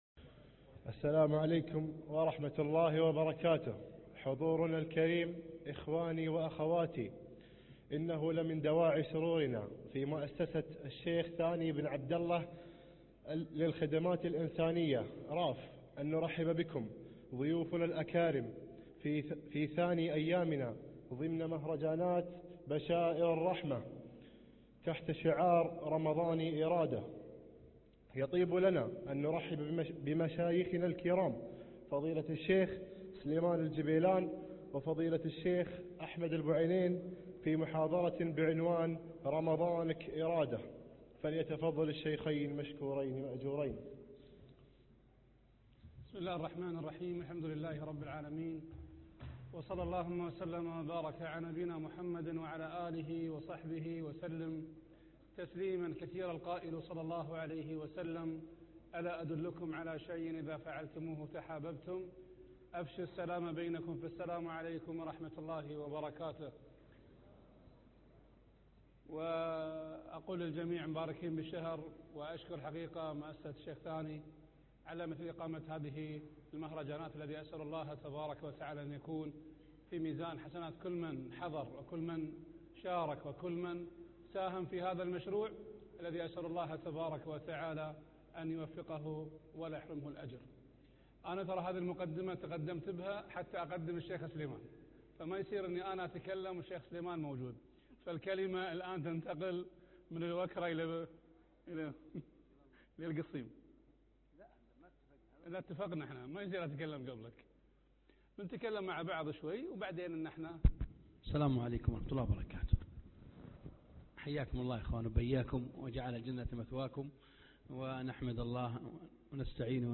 شبابنا والإرداة - مهرجان بشائر الرحمة